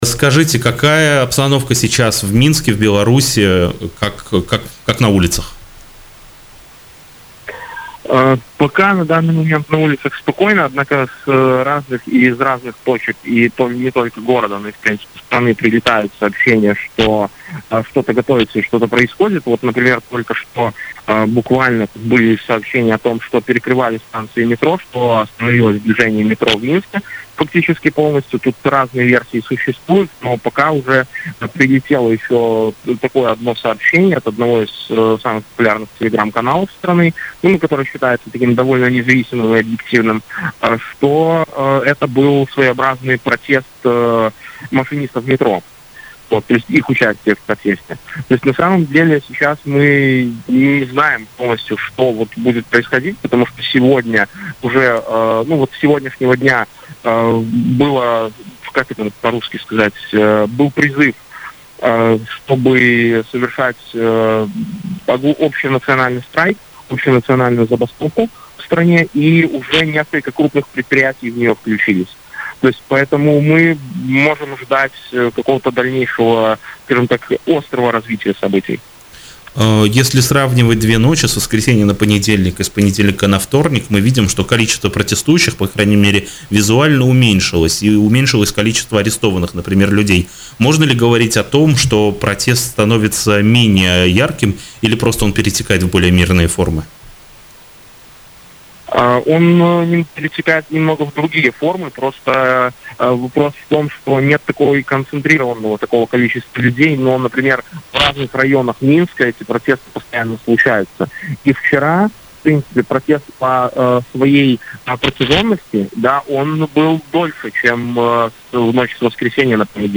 «Общенациональная забастовка и остановка метро»: белорусский журналист о ситуации в стране